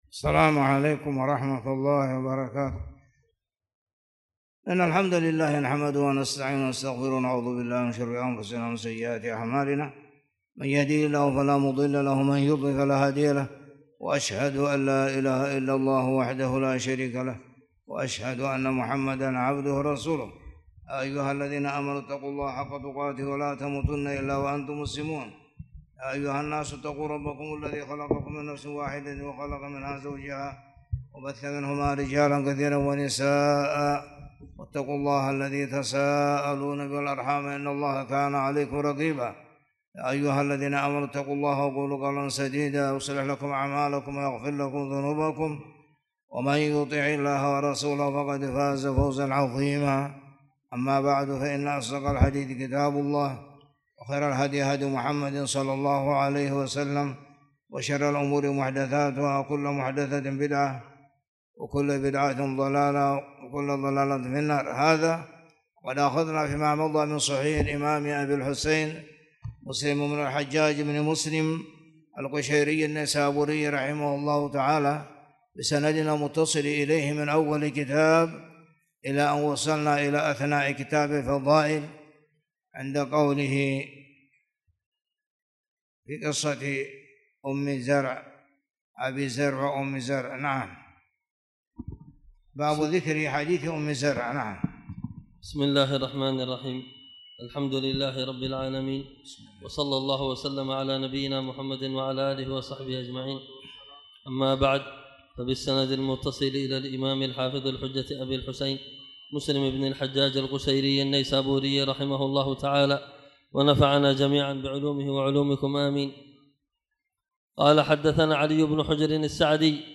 تاريخ النشر ٢١ صفر ١٤٣٨ هـ المكان: المسجد الحرام الشيخ